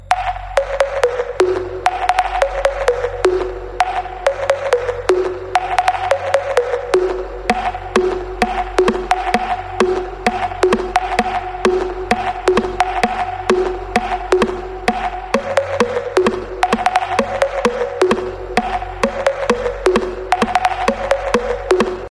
Descarga de Sonidos mp3 Gratis: ping pong 1.
ping-pong-1.mp3